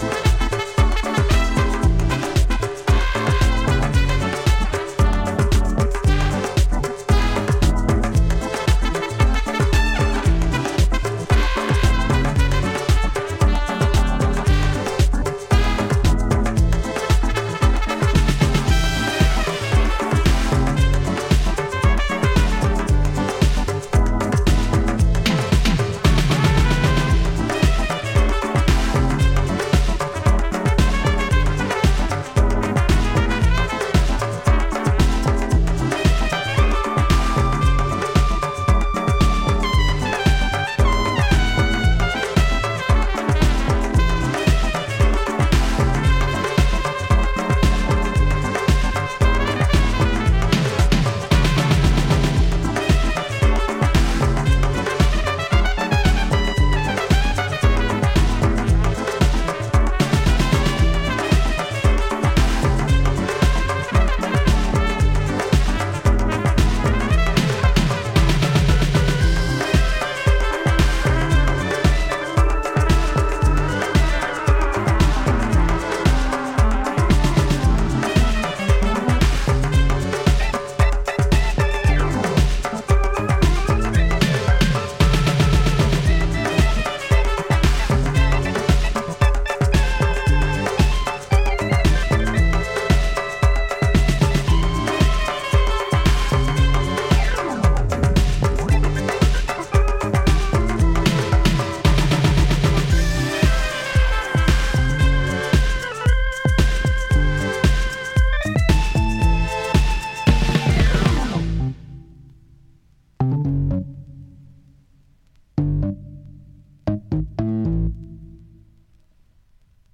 is a modern electronic gwoka fusion tune